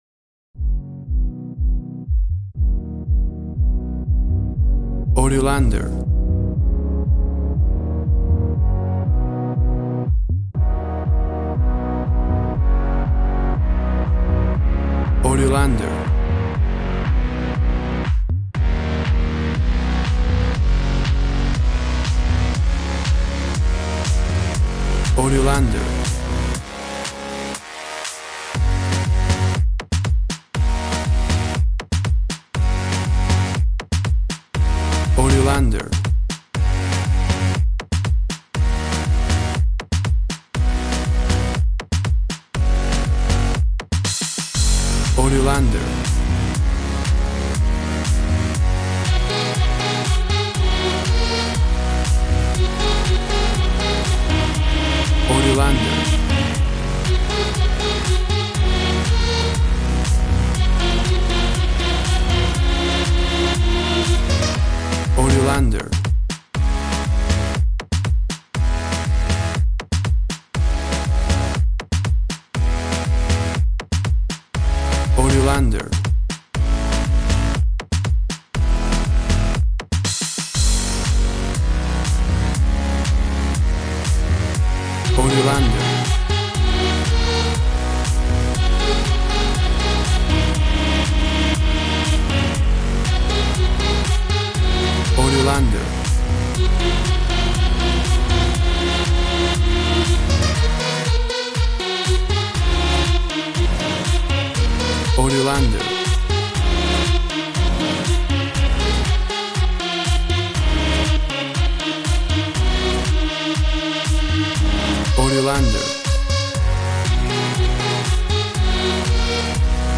WAV Sample Rate 16-Bit Stereo, 44.1 kHz
Tempo (BPM) 120